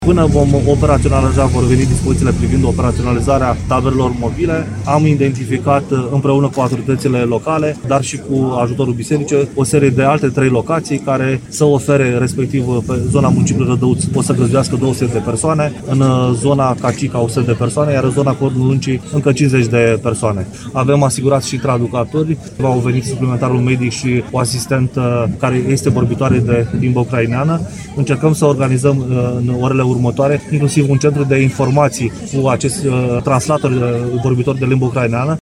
Prezent în Vama Siret, prefectul ALEXANDRU MOLDOVAN a declarat că va primi dispoziții pentru amplasarea taberei mobile, una dintre cele 3 aflate în dotarea Departamentului pentru Situații de Urgență.